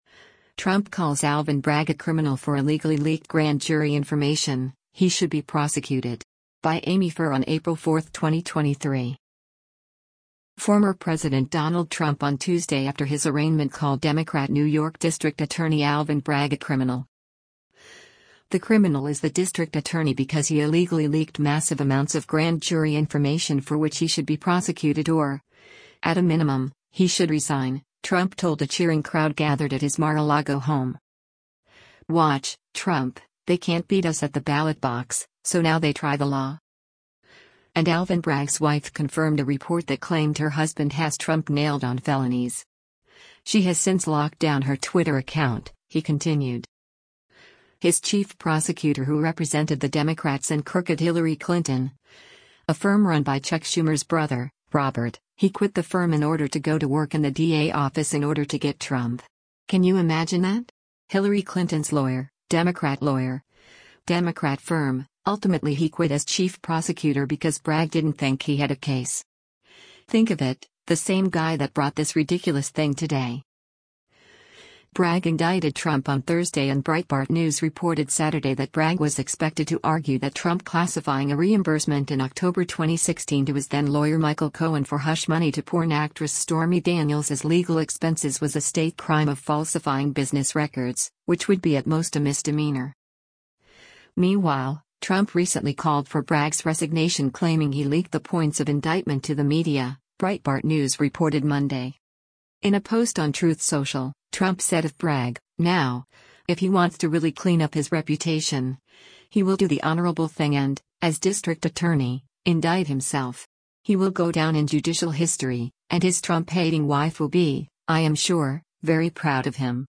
“The criminal is the district attorney because he illegally leaked massive amounts of grand jury information for which he should be prosecuted or, at a minimum, he should resign,” Trump told a cheering crowd gathered at his Mar-a-Lago home.